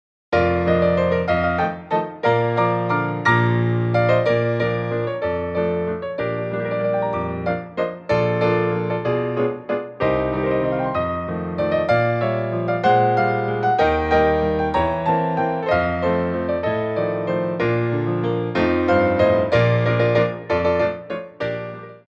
Grand Allegro 1
3/4 (16x8)